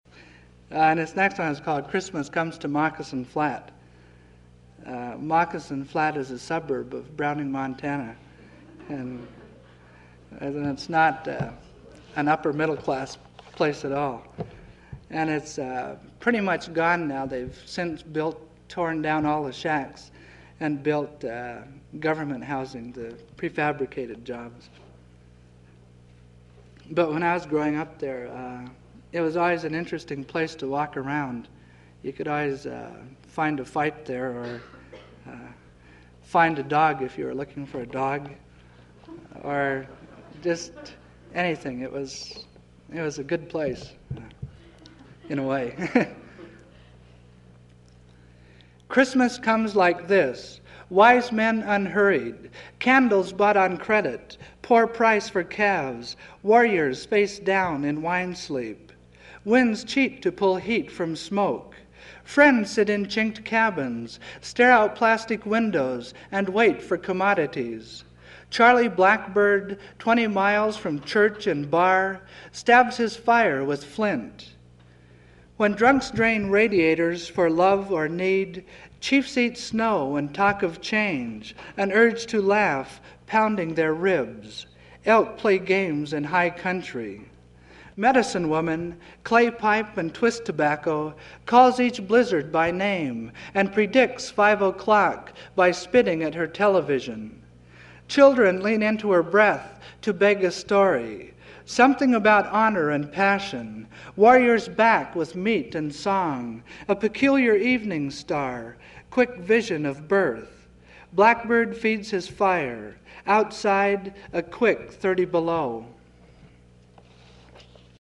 James Welch reads from his poetry collection Riding the Earthboy 40 (1976) and his novel Winter in the Blood (1974).